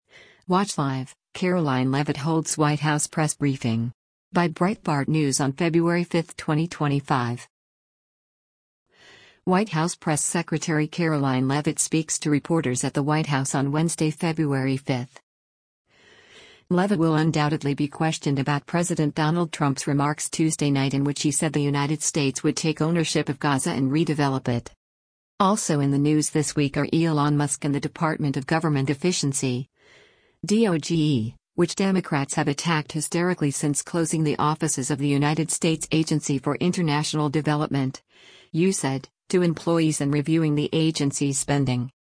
White House Press Secretary Karoline Leavitt speaks to reporters at the White House on Wednesday, February 5.